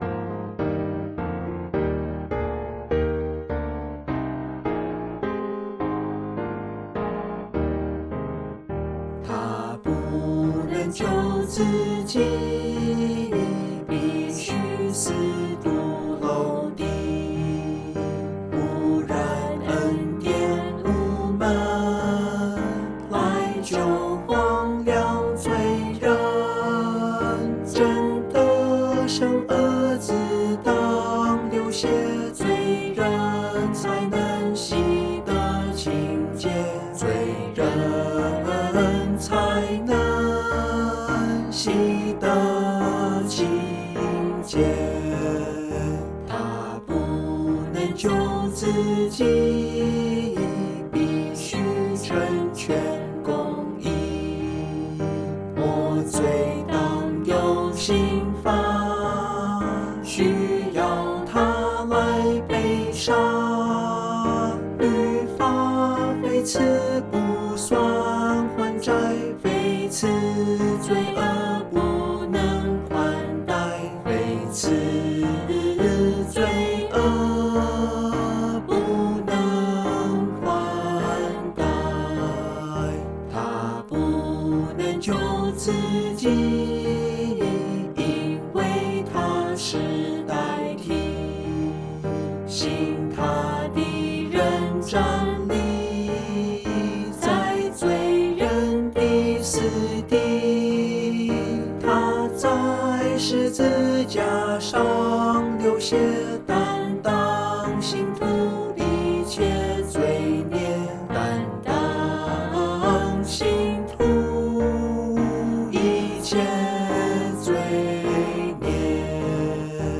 F Majeur